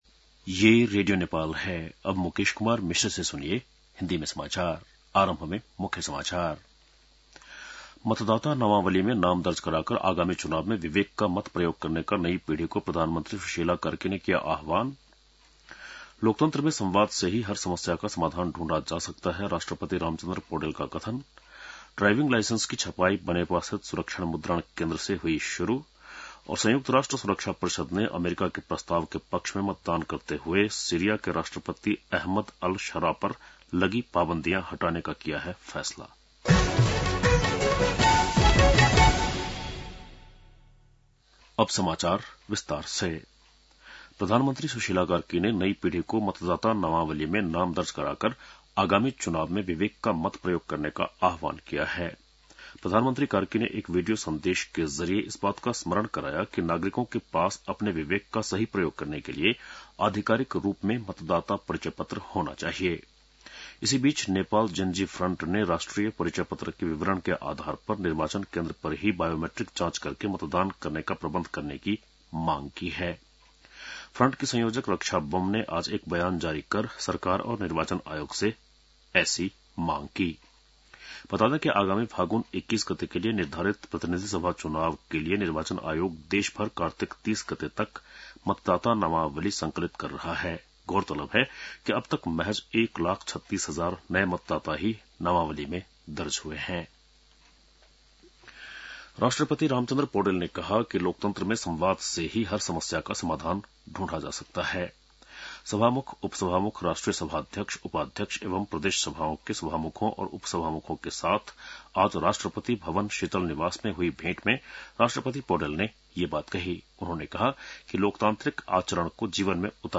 बेलुकी १० बजेको हिन्दी समाचार : २१ कार्तिक , २०८२